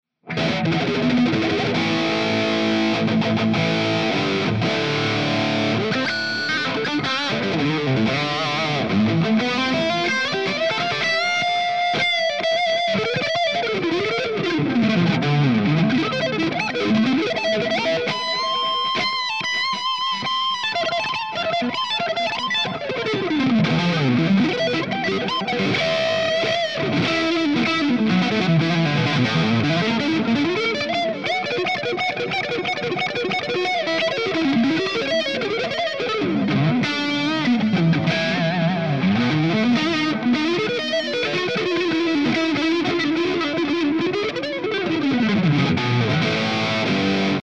The JCM900 pack includes captures ranging from clean tones to heavily distorted and everything in between plus my personal YouTube IR that I use in my demos are also included.
Improv
RAW AUDIO CLIPS ONLY, NO POST-PROCESSING EFFECTS